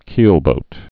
(kēlbōt)